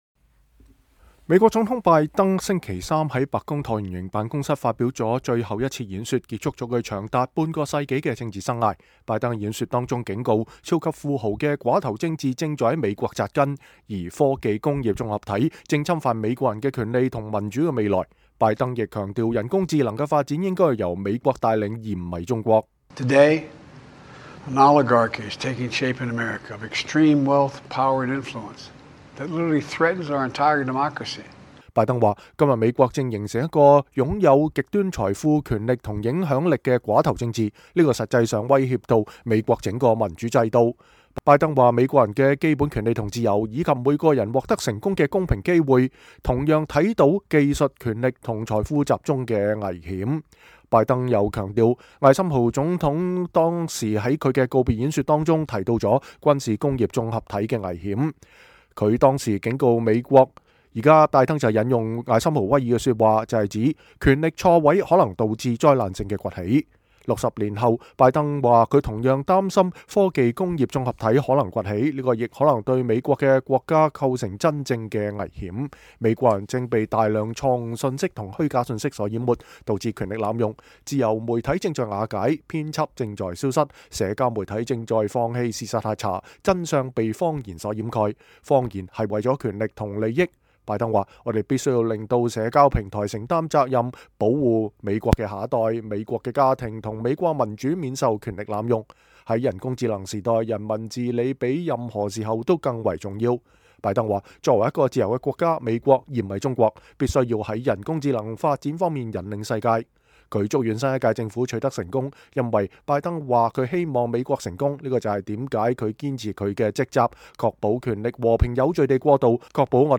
拜登總統在白宮橢圓形辦公室發表告別演說
美國總統喬·拜登(Joe Biden)星期三(1月15日)在白宮橢圓形辦公室發表告別演說，五天後他將結束總統任期，當選總統唐納德·特朗普(Donald Trump)屆時將宣誓就職。